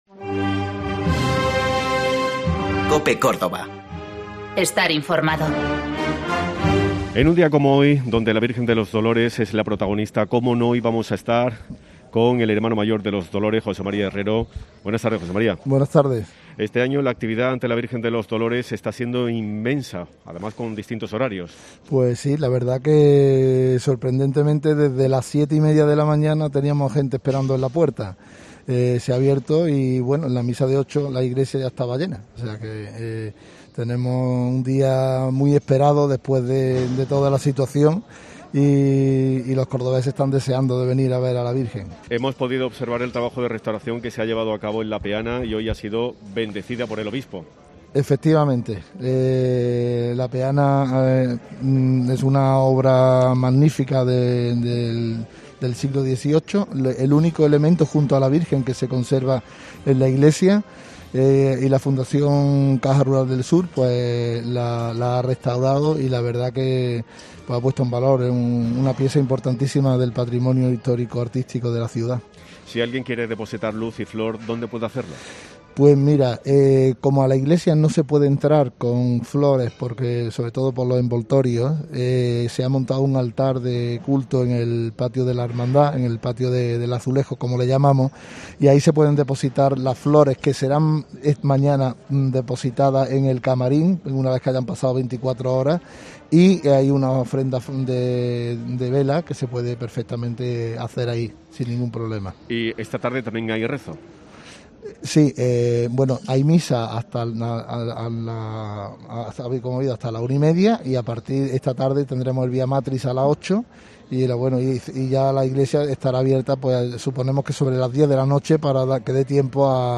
Cada viernes de Dolores, COPE se traslada a la plaza de capuchinos, al epicentro de la fe.